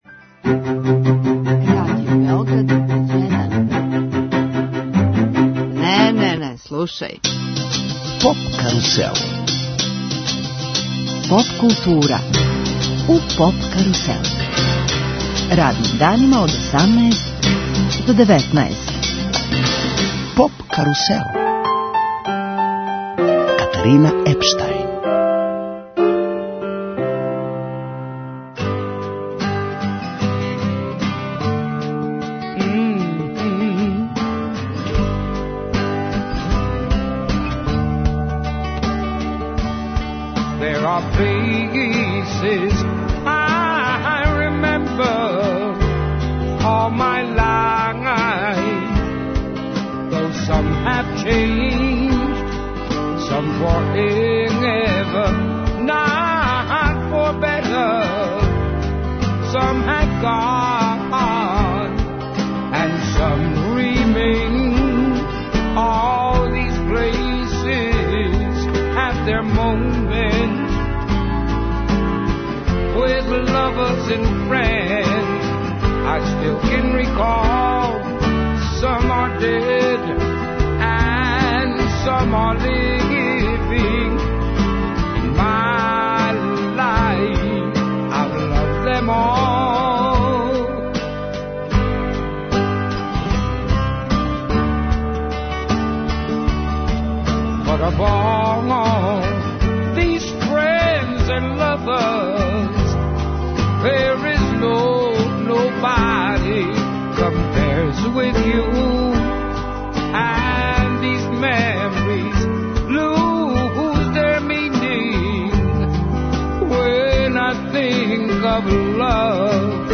Гост емисије је композитор Сања Илић (Балканика).